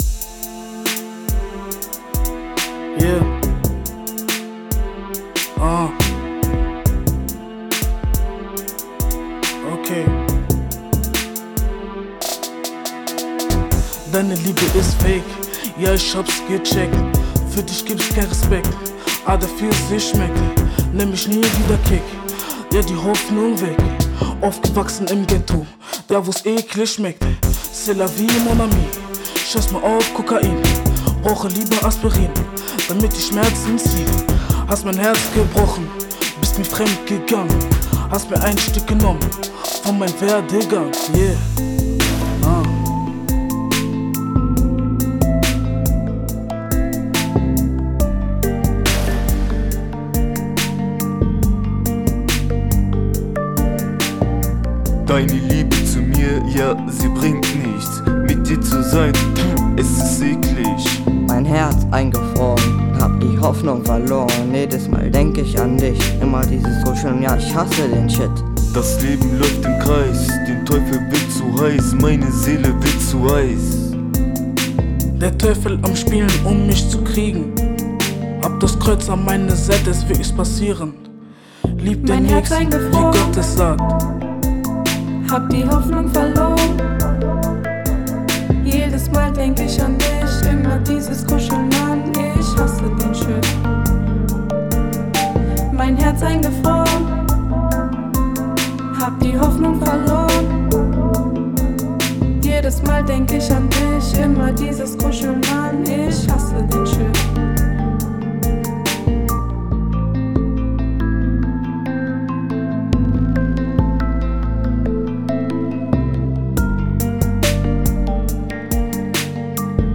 Hiphop an der Realschule Mausbach trotz Corona-Pandemie!
Eine kleine bunt gemischte Schülergruppe ließ sich nicht davon abhalten.
Hier nun der selbstproduzierte Song Mein Herz eingefroren